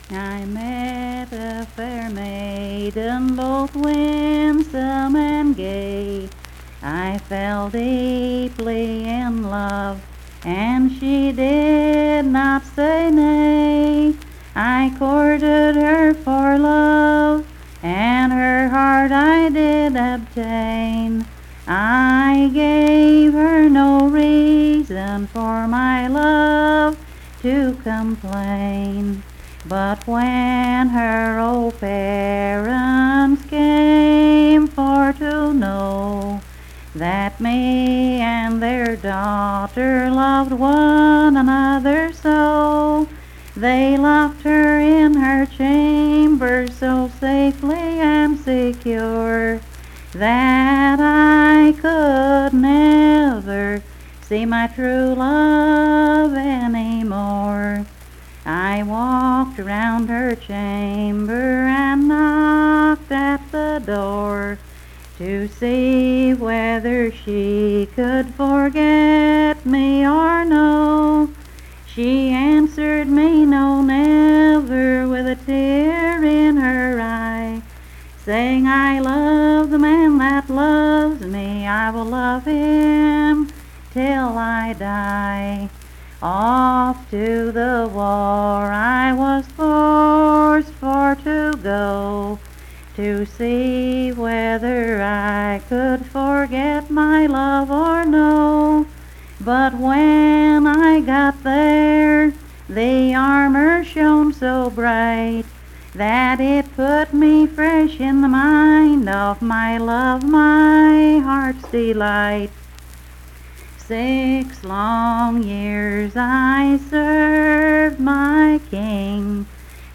Unaccompanied vocal music
Verse-refrain 6(4).
Performed in Coalfax, Marion County, WV.
Voice (sung)